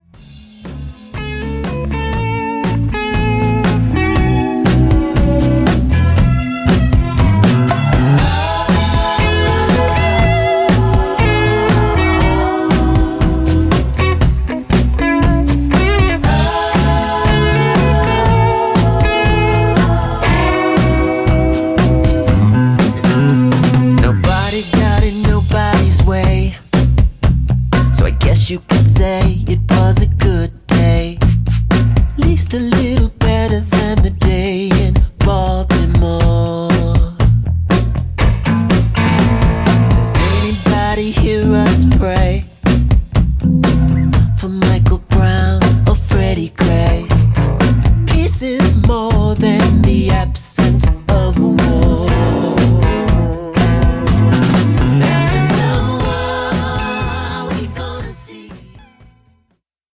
ホーム ｜ SOUL / FUNK / RARE GROOVE / DISCO > SOUL
’70年代ブラック・ミュージックの良質なエッセンスがストレートに盛り込まれた好曲が揃った